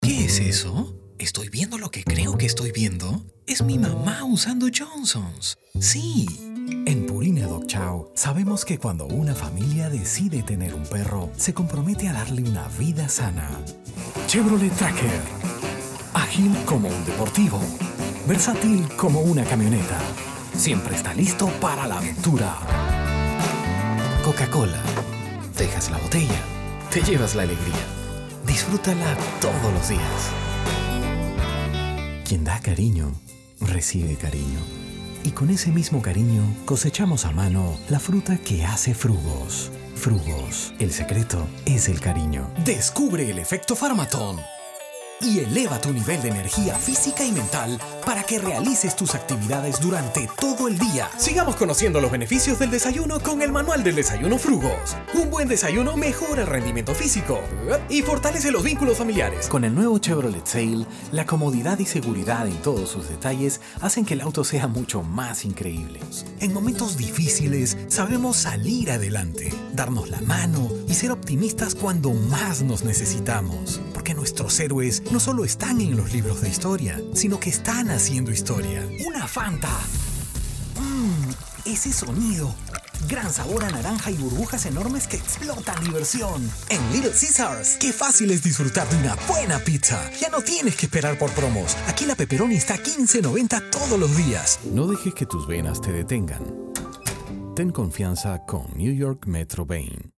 Voz versátil, cálida y de amplio rango vocal.
Sprechprobe: Werbung (Muttersprache):
Versatile, warm voice with a wide vocal range.
Professional recording studio.